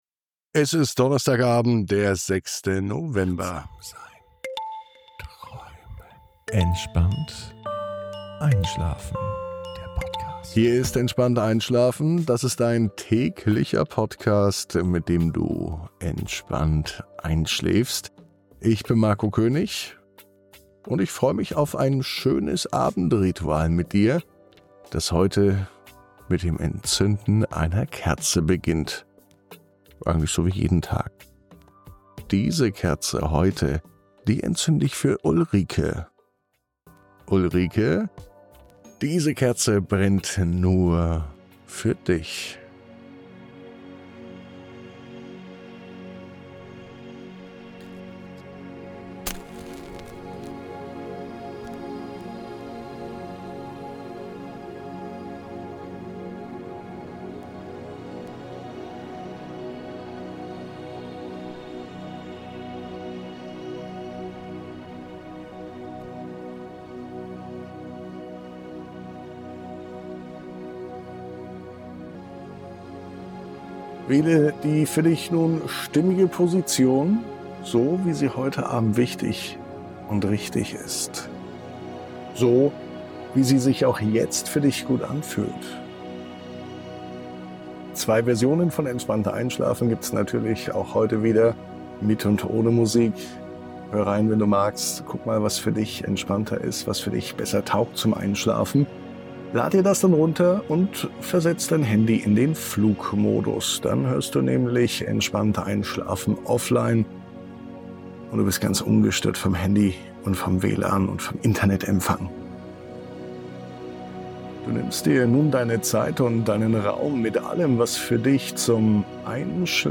Während das goldene Licht des Sonnenuntergangs die Umgebung in warme Farben taucht, begleiten dich leise Wellen und eine friedliche Stimmung in die Ruhe der Nacht.